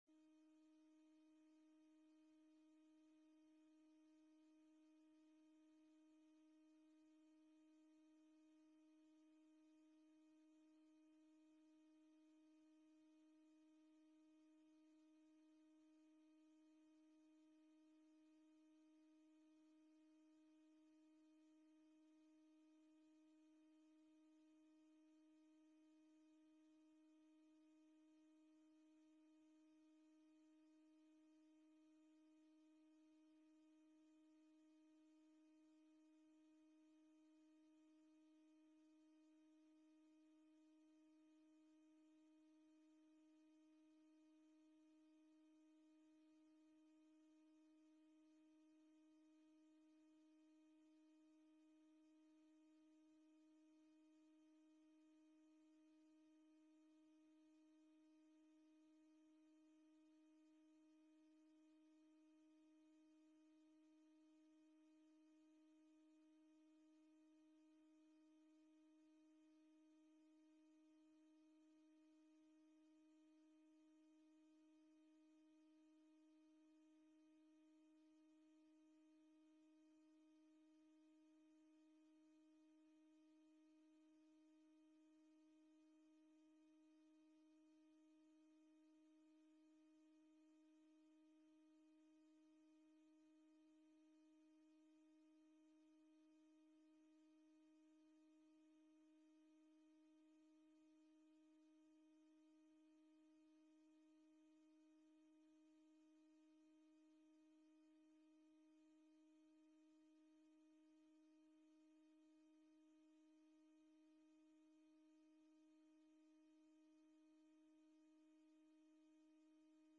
Agenda Nieuwegein - Raadsvergadering Zaal 008 donderdag 24 september 2020 20:00 - 23:00 - iBabs Publieksportaal
De vergadering wordt gehouden in het Stadshuis met in achtneming van de 1,5 meter regel.
Locatie Stadshuis Voorzitter Frans Backhuijs Toelichting De vergadering wordt gehouden in het Stadshuis met in achtneming van de 1,5 meter regel.